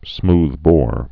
(smthbôr)